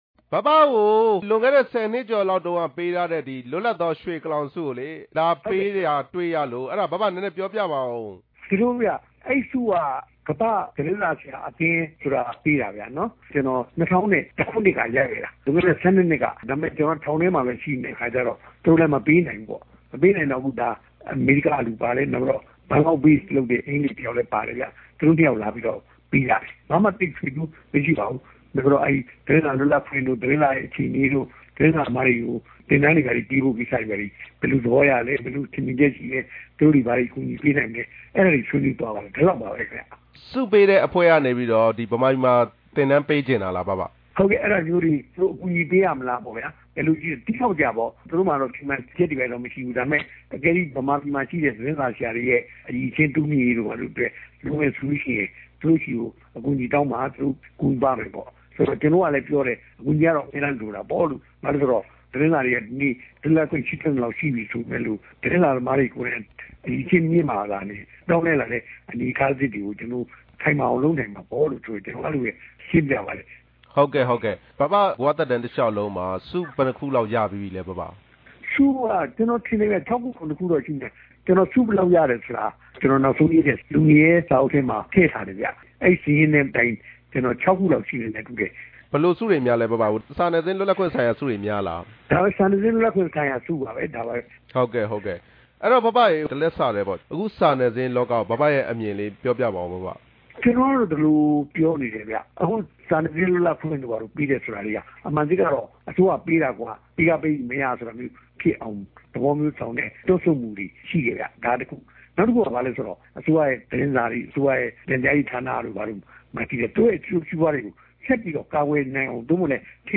သတင်းစာဆရာကြီး ဟံသာဝတီ ဦးဝင်းတင်နဲ့ မေးမြန်းချက်